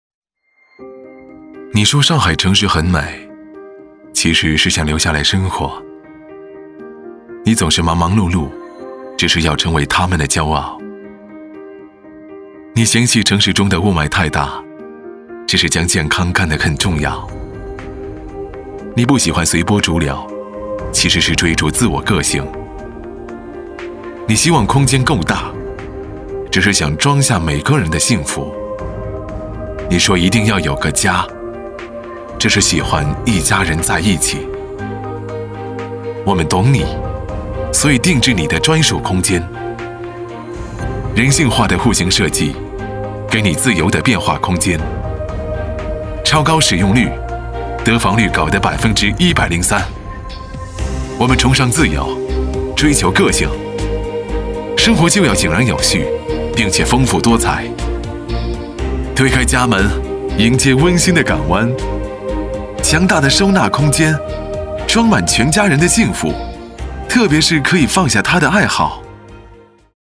男国294_宣传片_地产_碧桂园嘉誉.mp3